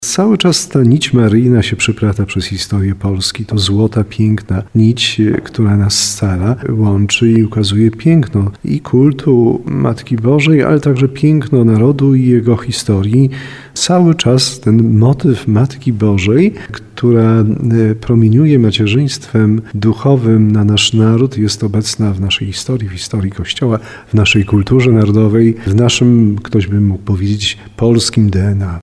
Matka Boża wpisała się w dzieje Polski na różne sposoby – mówi biskup tarnowski Andrzej Jeż. 3 maja w liturgii przypada uroczystość Najświętszej Maryi Panny Królowej Polski – głównej patronki naszej Ojczyzny.